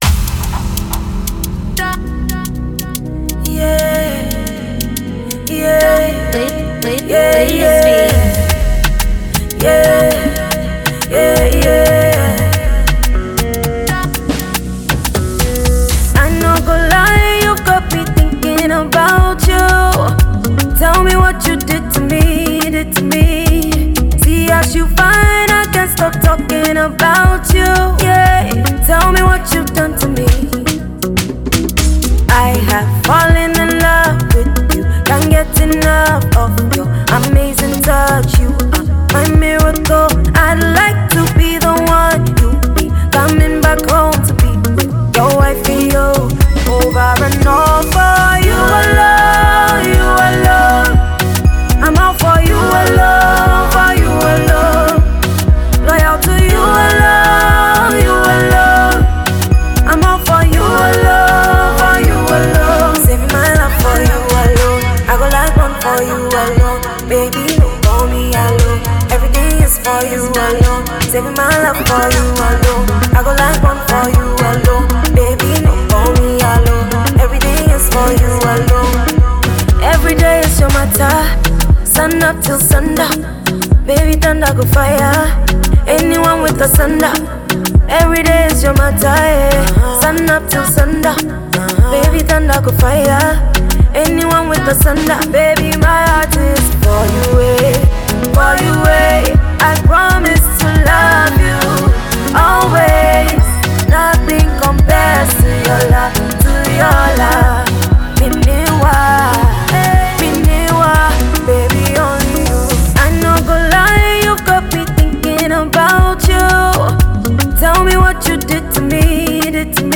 Multiple Award-winning Ghanaian songstress
groovy hit song